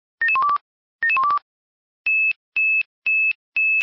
Alarmierung
und 2007 vom Typ Swissphone Quattro XLSi  beschafft .